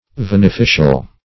Search Result for " veneficial" : The Collaborative International Dictionary of English v.0.48: Veneficial \Ven`e*fi"cial\, Veneficious \Ven`e*fi"cious\, a. Acting by poison; used in poisoning or in sorcery.